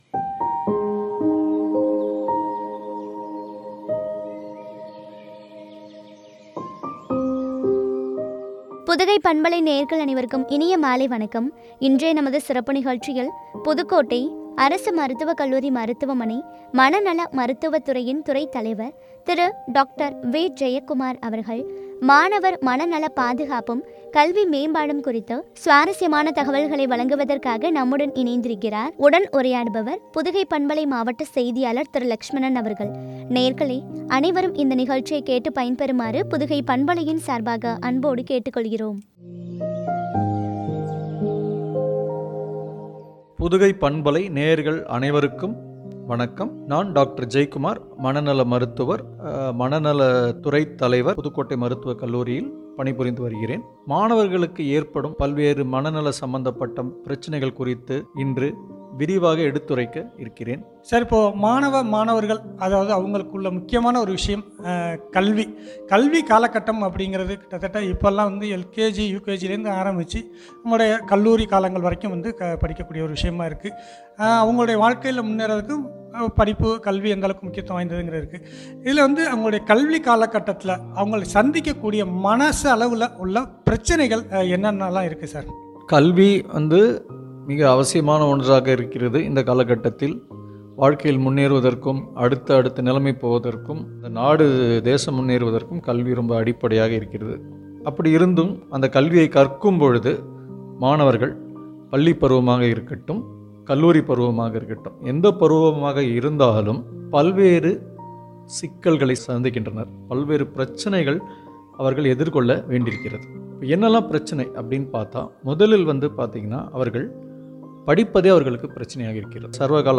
மாணவர் மன நல பாதுகாப்பும், கல்வி மேம்பாடும் பற்றிய உரையாடல்.